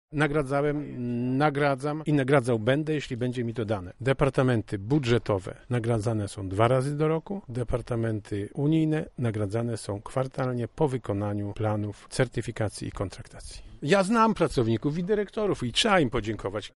– mówi Sławomir Sosnowski, ustępujący Marszałek Województwa Lubelskiego